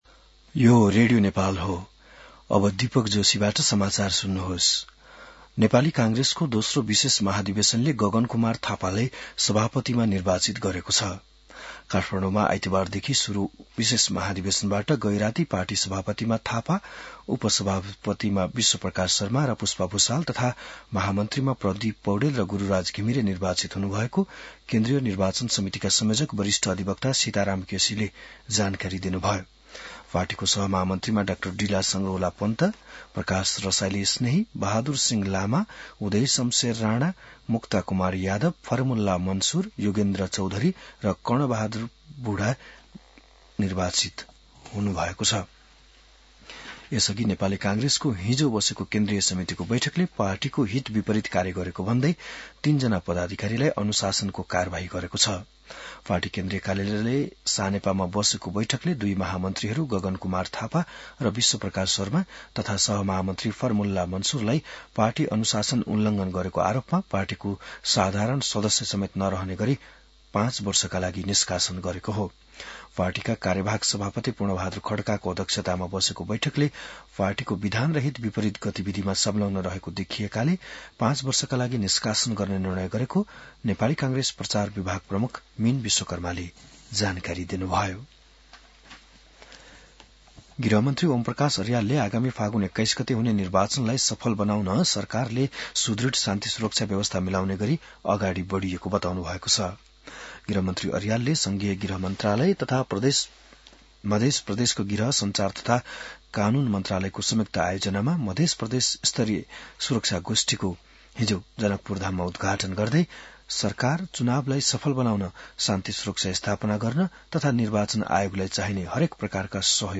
बिहान १० बजेको नेपाली समाचार : १ माघ , २०८२